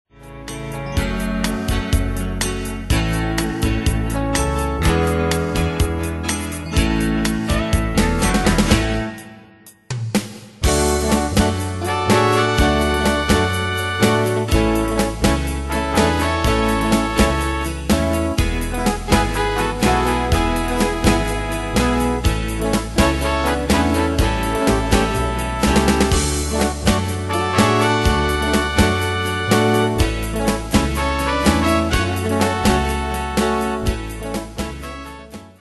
Demos Midi Audio
Style: Country Année/Year: 2002 Tempo: 124 Durée/Time: 3.03
Danse/Dance: Country Cat Id.
Pro Backing Tracks